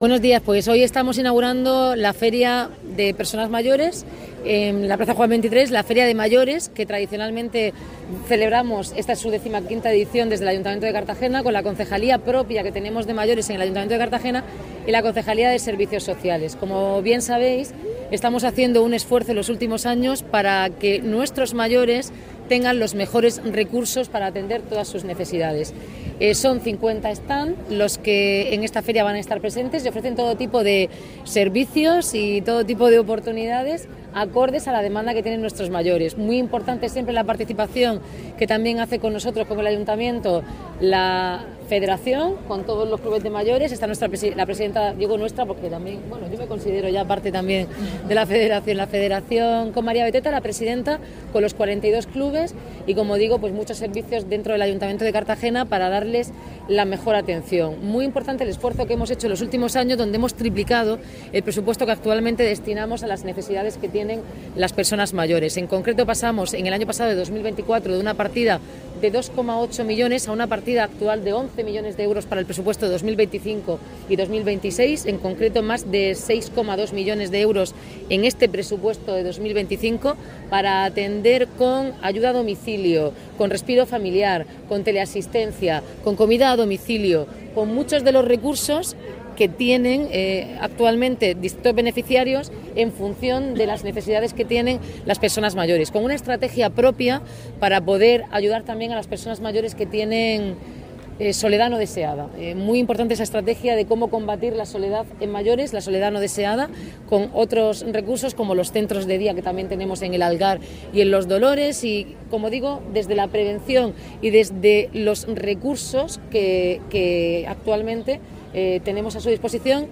Enlace a Declaraciones de la alcaldesa, Noelia Arroyo